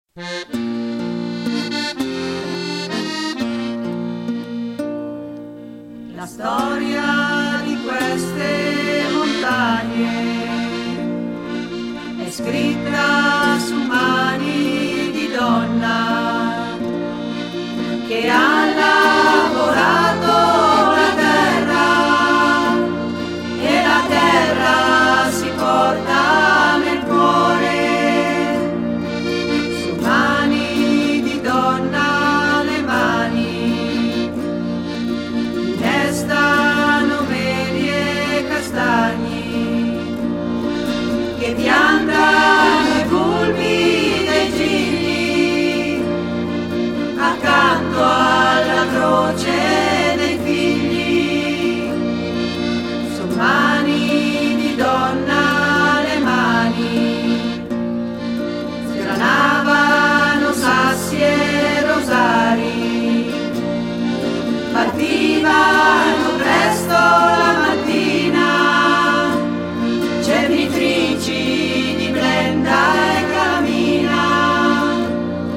Taissine: cernitrici di minerale nelle miniere bergamasche [Gruppo folklorico]
Le donne nella tradizione montana, un canto popolare ne descrive le gesta, le loro mani hanno scritto la storia.